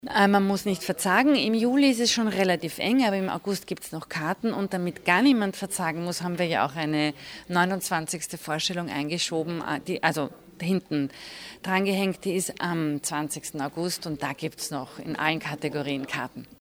O-Ton Pressetag News